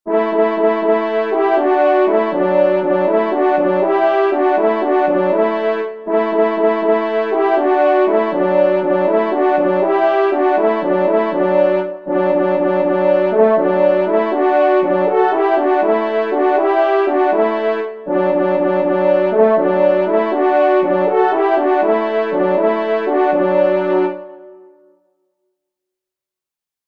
Musique Synthé “French Horns” (Tonalité de Ré
Retraite-de-grace-Duo.mp3